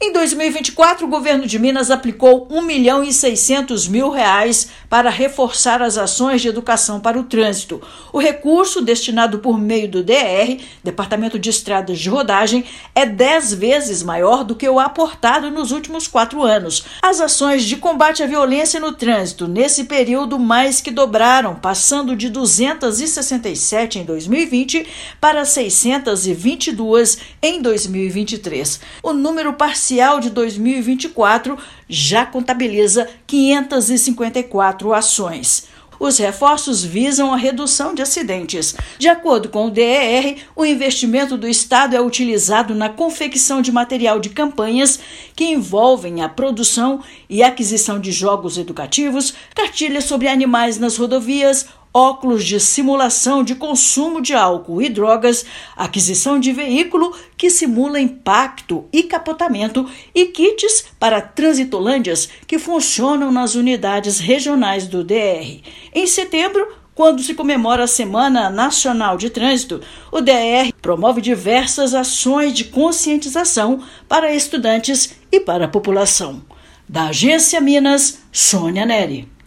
Volume de recursos é dez vezes maior que o praticado nos últimos quatro anos. Ouça matéria de rádio.